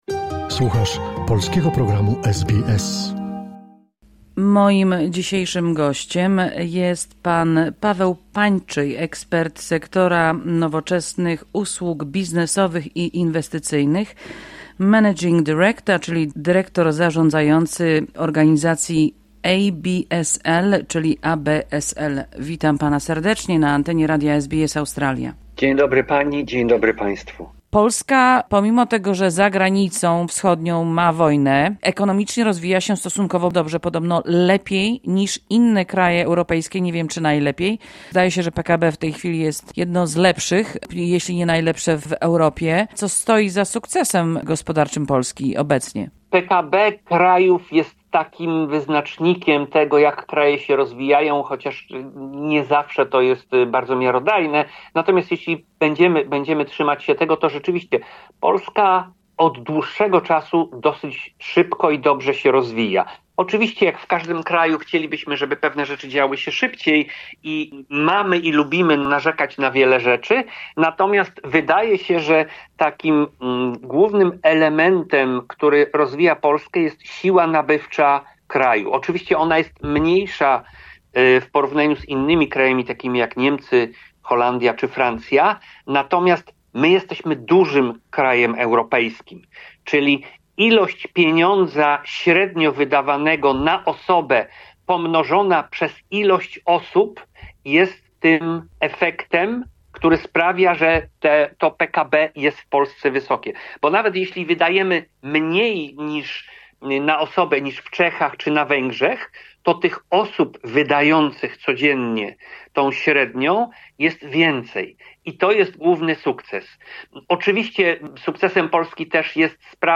W dzisiejszej romowie - o tym co stoi za sukcesem gospodarczym współczesnej Polski i jaki wplyw na ten sukces ma Polonia i Polacy mieszkajacy poza granicami Polski..?